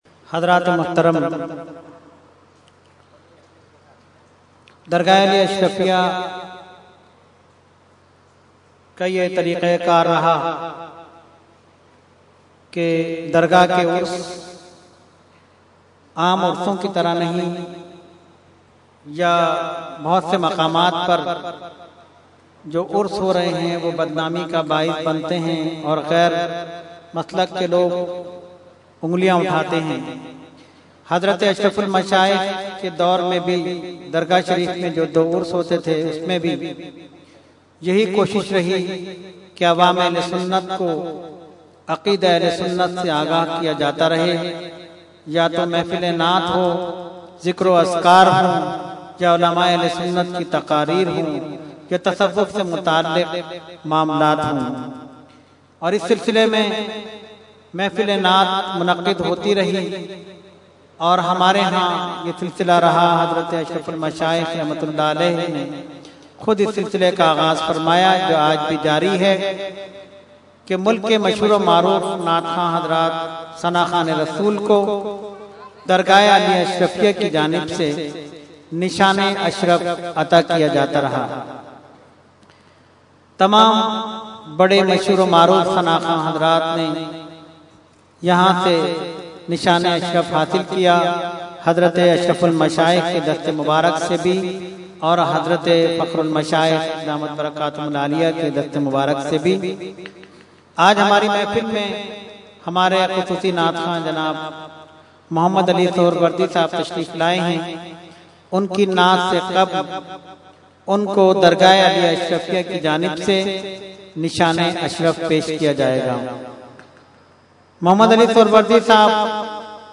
21-Nishan e Ashraf Announcement.mp3